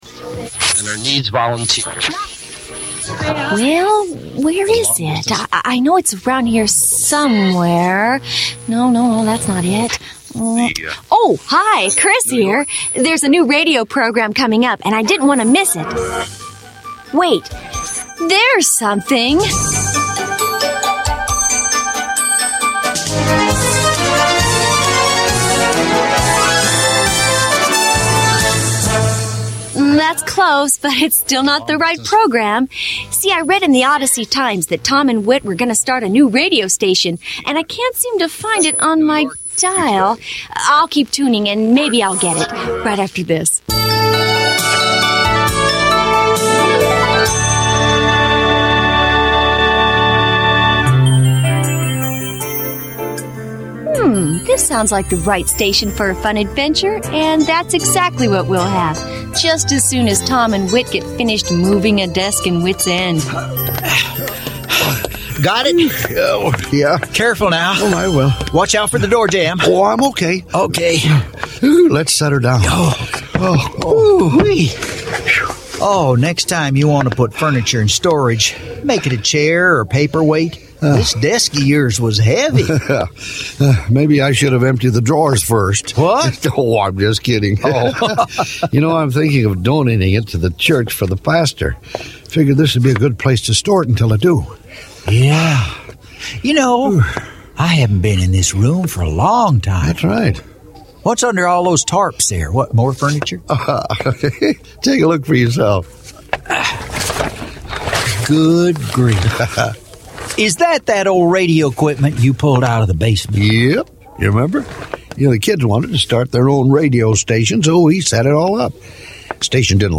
Created for ages 8-12 but enjoyed by the whole family, Adventures in Odyssey presents original audio stories brought to life by actors who make you feel like part of the experience. These fictional, character-building dramas are created by an award-winning team that uses storytelling to teach lasting truths.